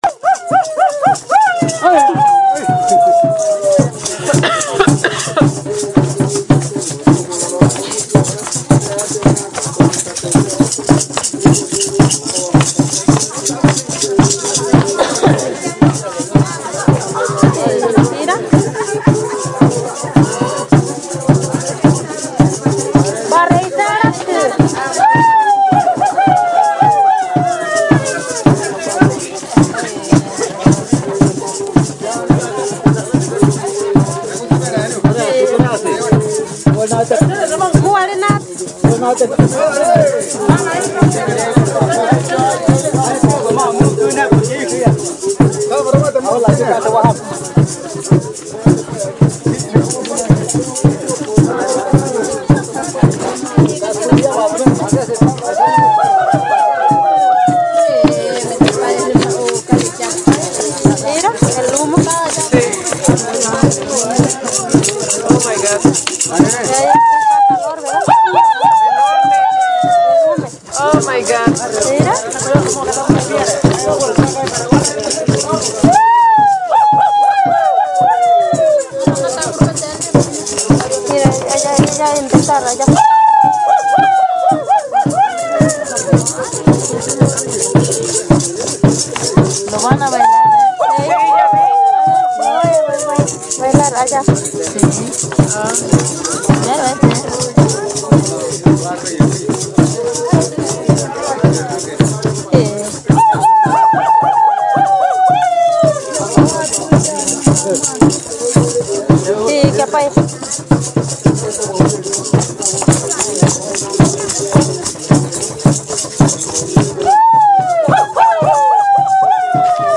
Audio 1 (mpga) Ejemplo sonoro. En la danza los Ni’ariwamete apagan el ocote que prendieron fuego. Centro ceremonial Tierra Azul, Jalisco